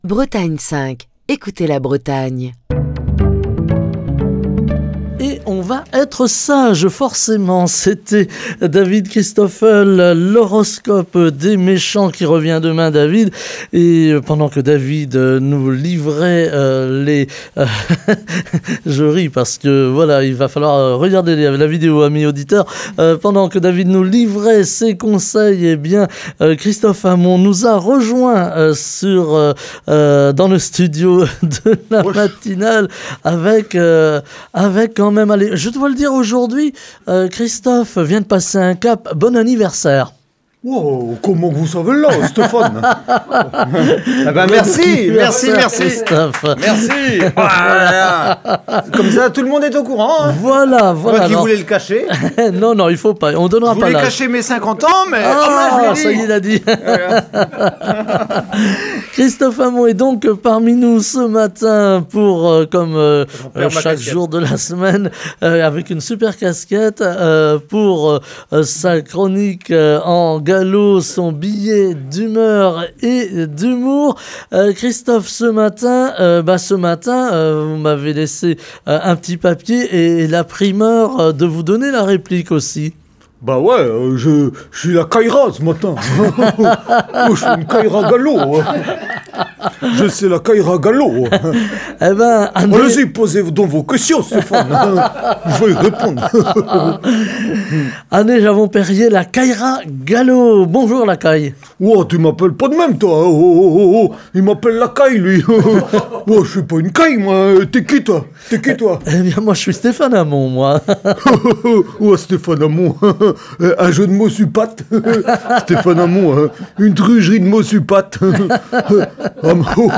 Chronique du 23 janvier 2023.